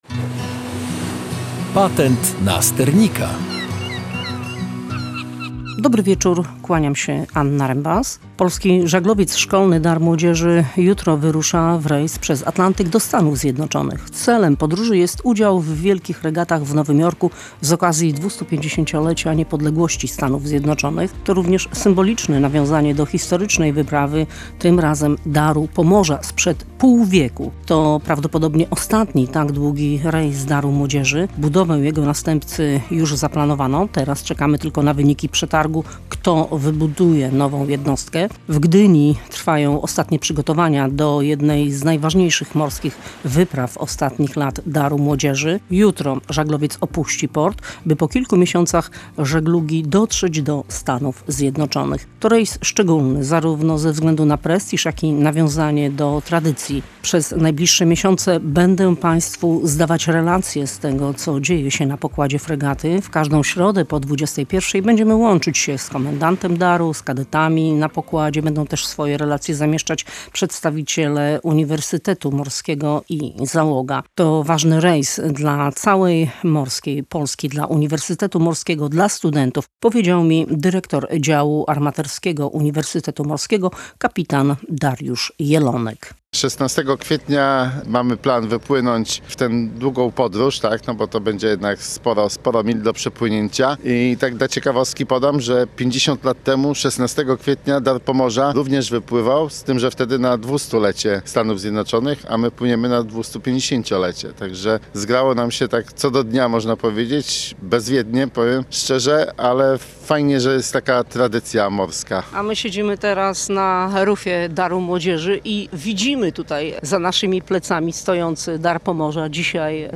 W audycji odwiedziliśmy też Sailing Factory Club w Górkach Zachodnich.